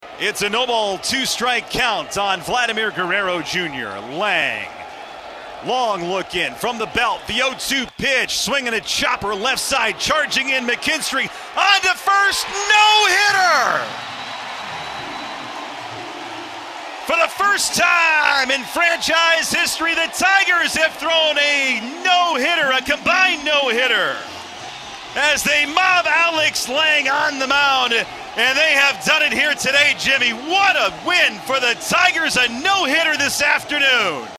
08-final-call-tigers-finish-combined-no-hitter-2-0-f.mp3